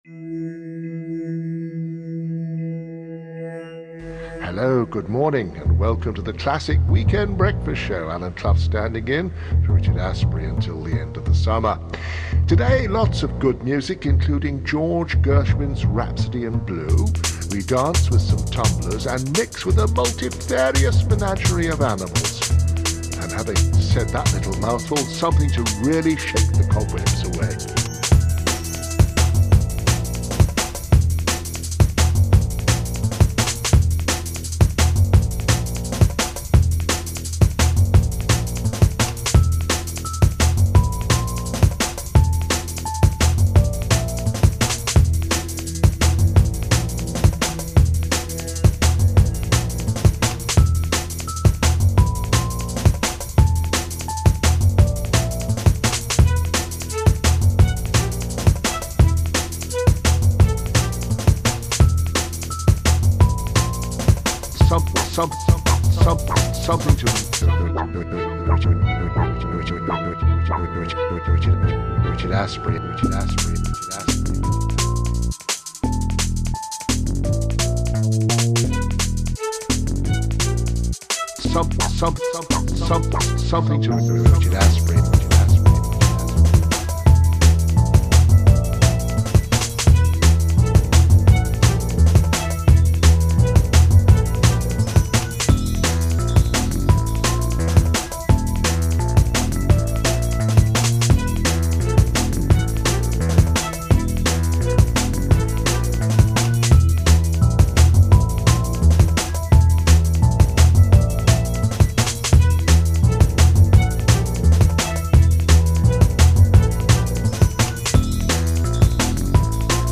Slight crazy intro tune.